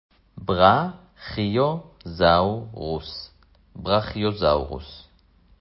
בר-כיו-זאו-רוס